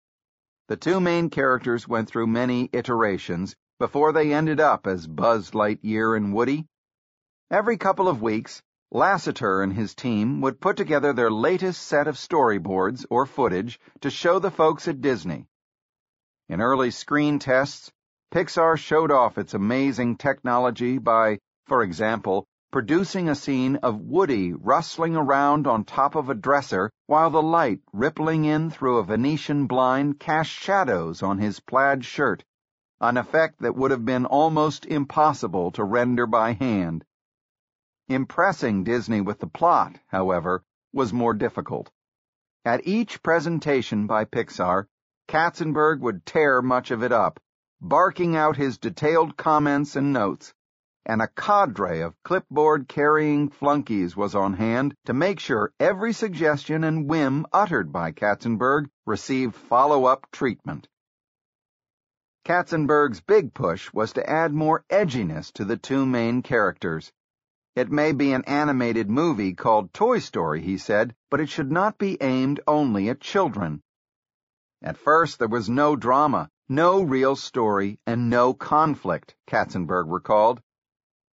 在线英语听力室乔布斯传 第332期:杰弗里·卡曾伯格(5)的听力文件下载,《乔布斯传》双语有声读物栏目，通过英语音频MP3和中英双语字幕，来帮助英语学习者提高英语听说能力。
本栏目纯正的英语发音，以及完整的传记内容，详细描述了乔布斯的一生，是学习英语的必备材料。